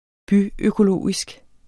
Udtale [ ˈbyøkoˌloˀisg ]